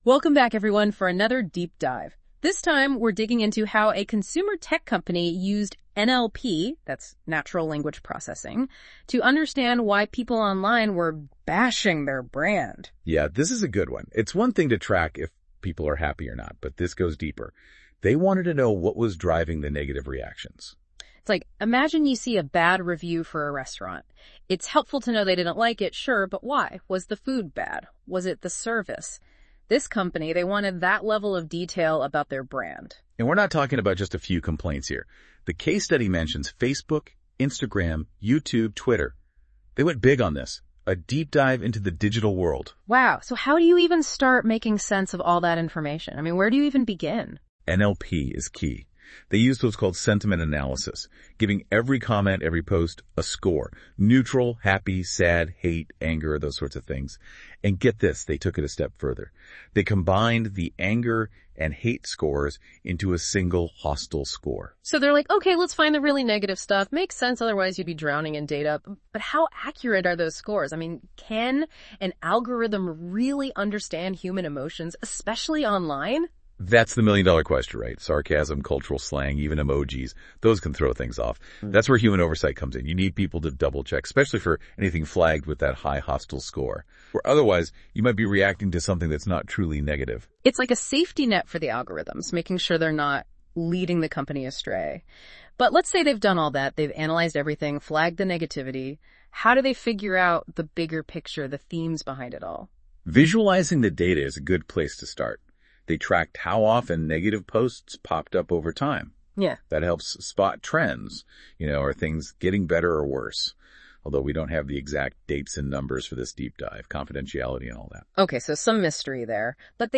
Note : This Podcast is generated through Notebook LM.